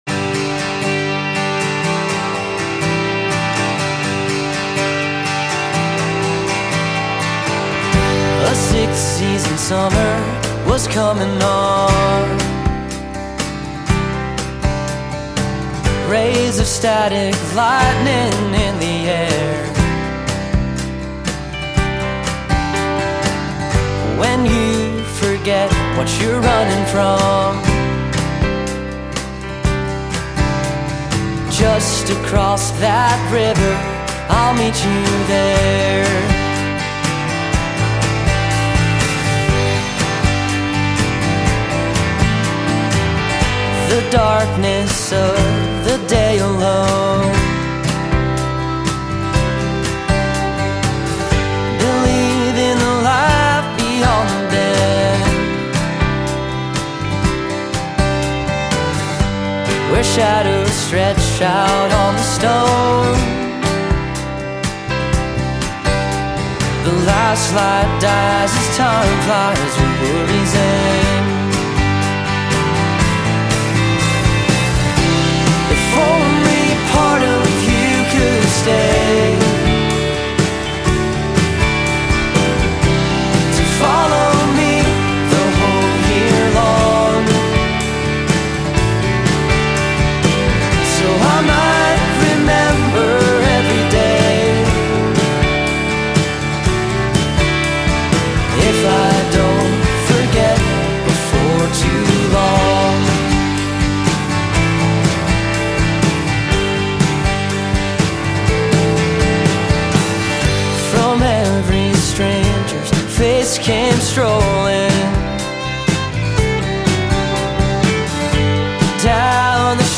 At Tank Recording Studio & The Barn